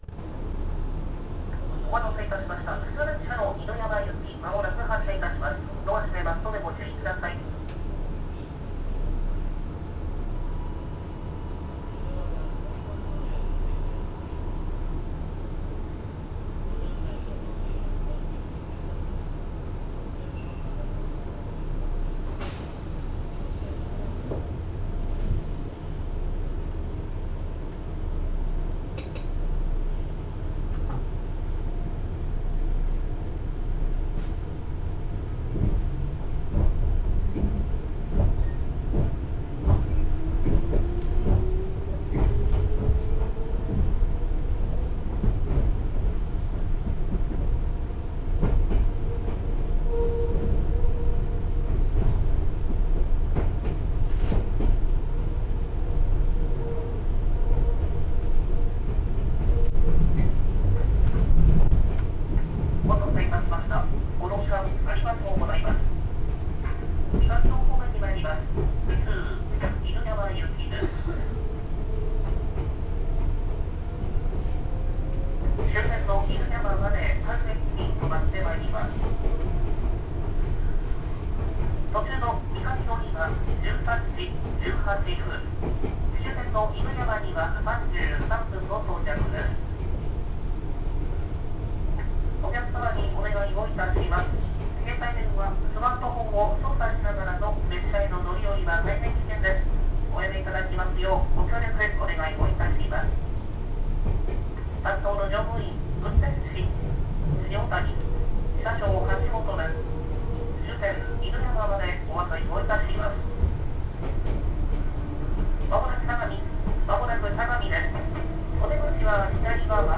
・5300系走行音
ただ、随分と渋い音で、あまりチョッパらしくないような気も…。